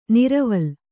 ♪ niraval